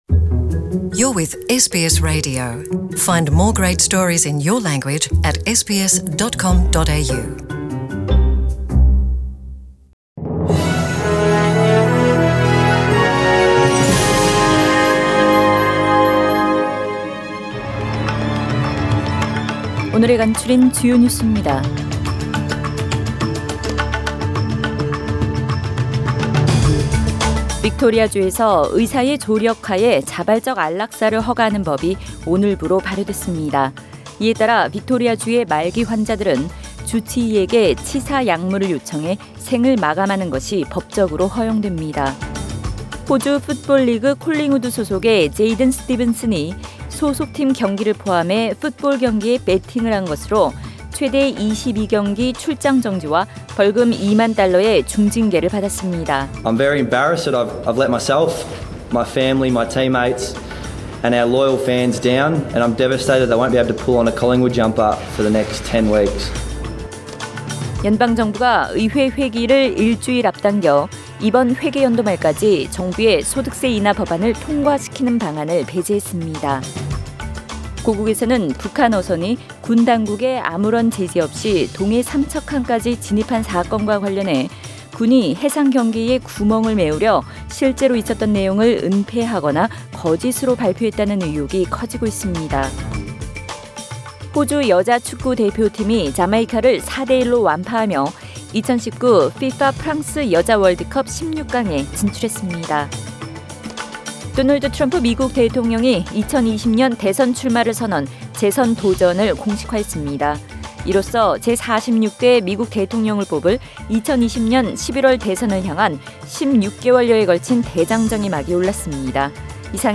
SBS 한국어 뉴스 간추린 주요 소식 – 6월 19일 수요일
2019년 6월 19일 수요일 저녁의 SBS Radio 한국어 뉴스 간추린 주요 소식을 팟 캐스트를 통해 접하시기 바랍니다.